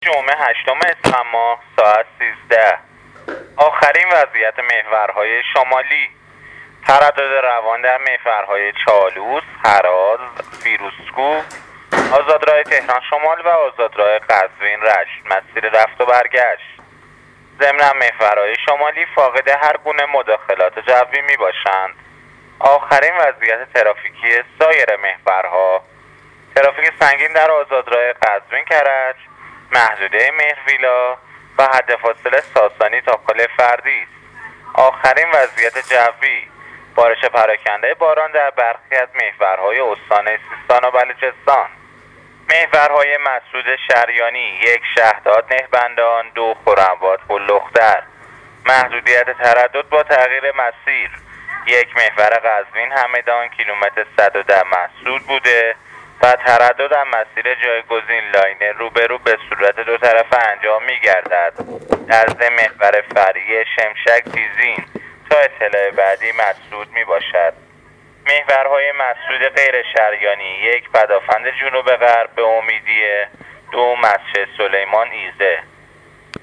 گزارش رادیو اینترنتی از آخرین وضعیت ترافیکی جاده‌ها تا ساعت ۱۳ روز جمعه ۸ اسفند